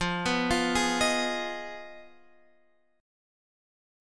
Fm7b5 chord